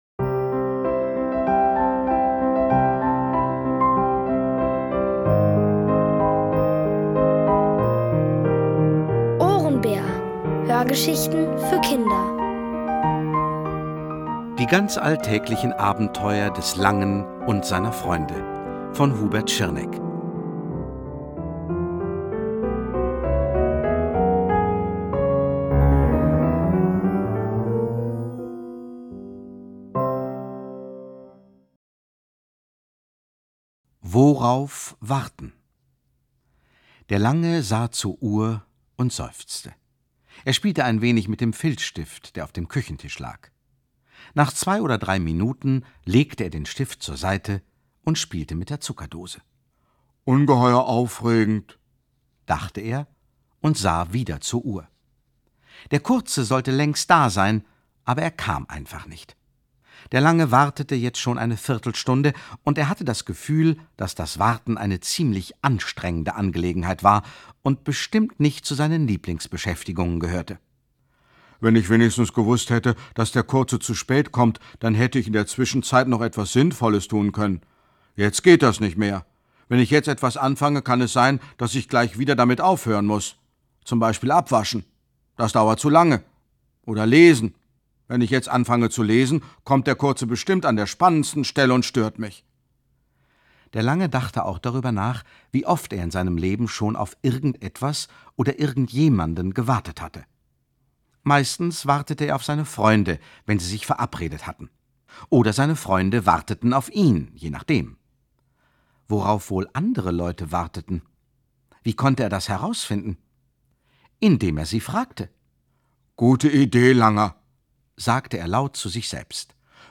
Von Autoren extra für die Reihe geschrieben und von bekannten Schauspielern gelesen.
liest: Gerd Wameling.